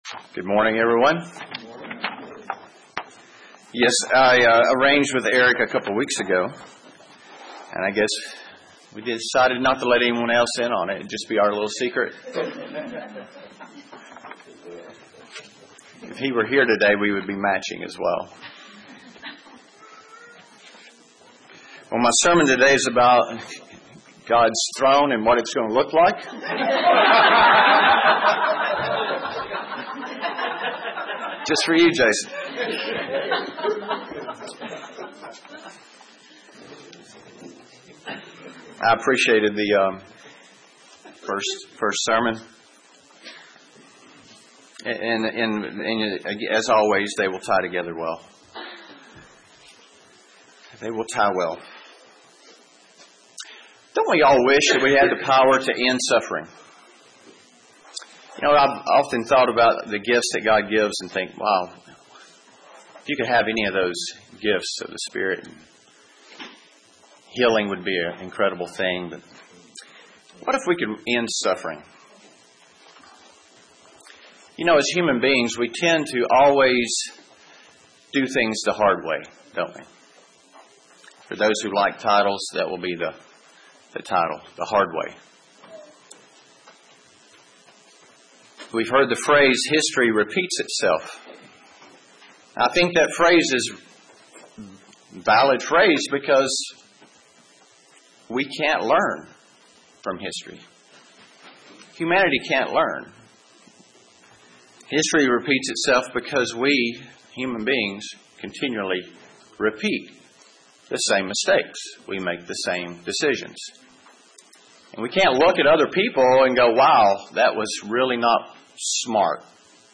UCG Sermon Studying the bible?
Given in Murfreesboro, TN